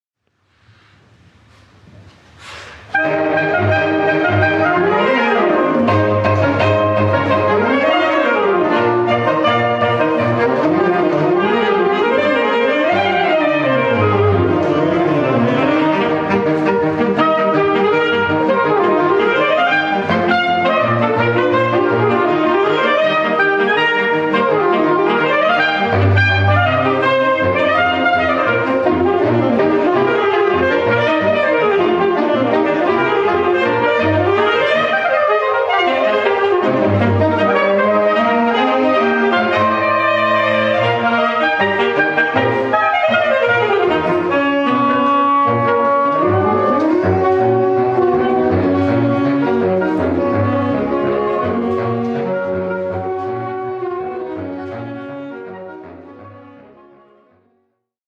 Besetzung: 5 Saxophones (SAATB)